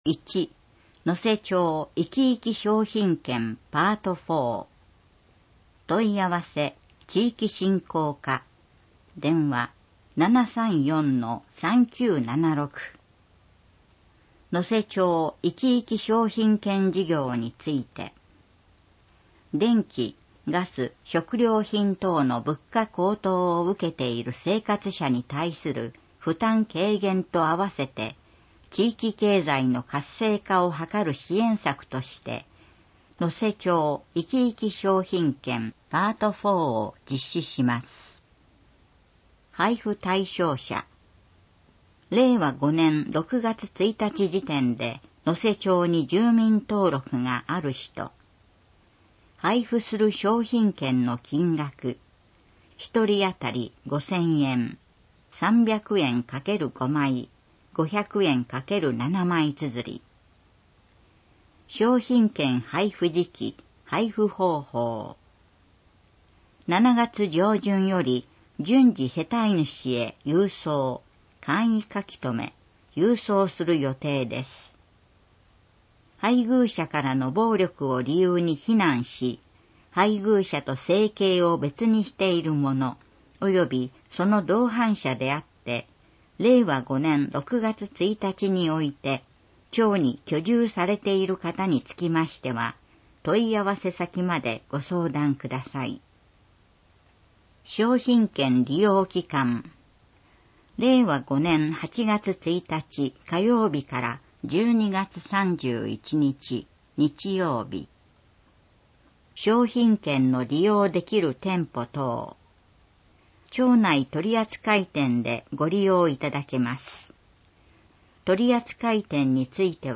このページは、目の不自由な方や、ご高齢により読むことが難しくなった方をはじめ、多くの皆さんに町政の情報をお知らせするため、広報「のせ」を朗読した声の広報「のせ」を掲載しています。